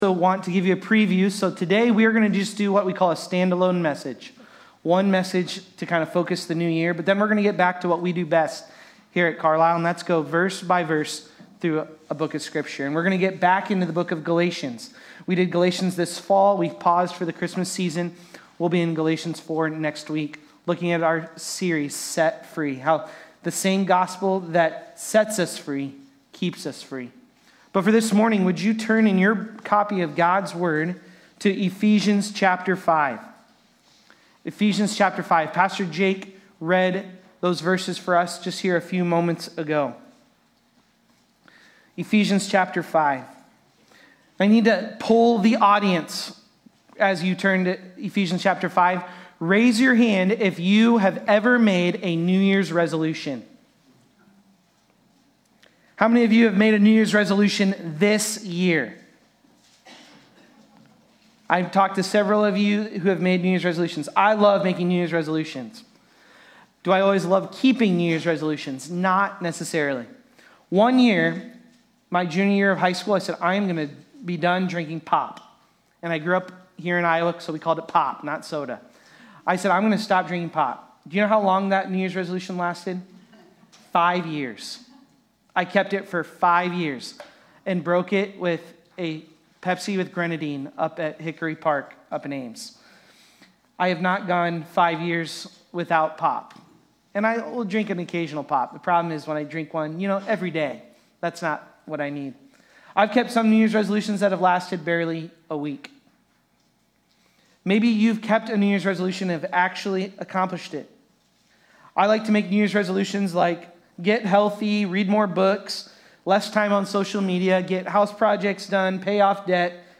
Eph.-5.15-16-Sermon-Audio.mp3